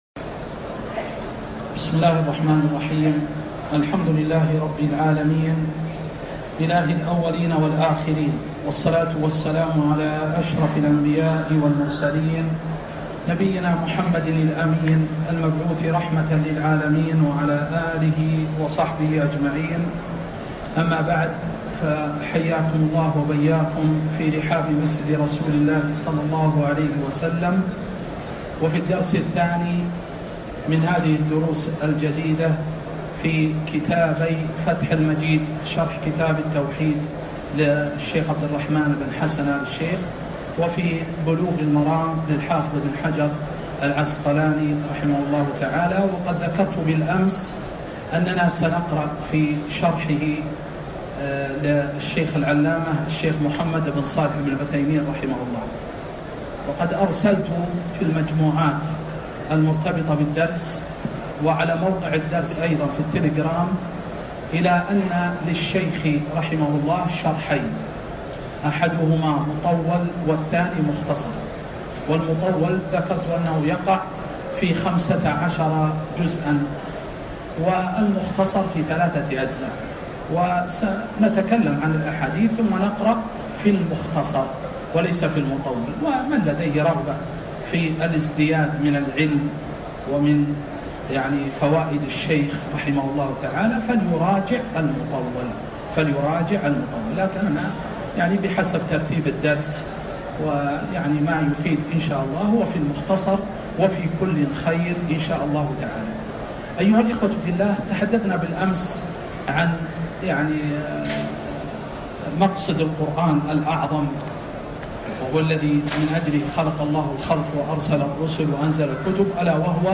الدرس (2) شرح كتاب فتح المجيد وكتاب بلوغ المرام